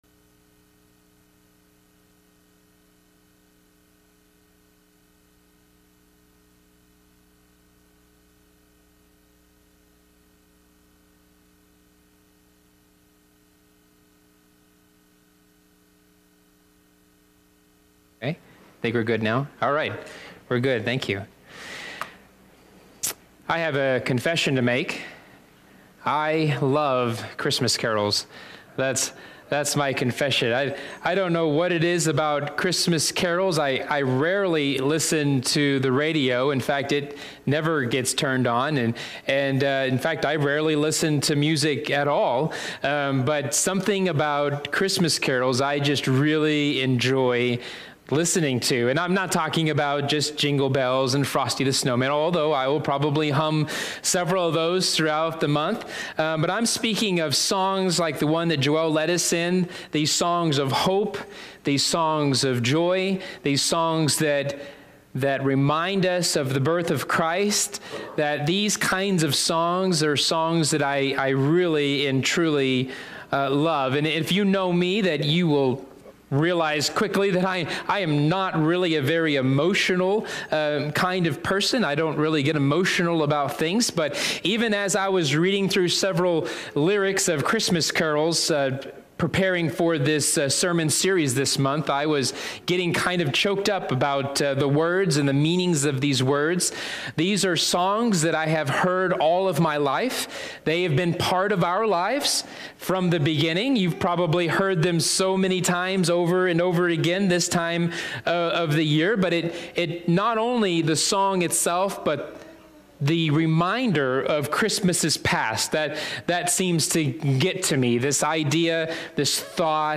All Sermons Joy To The World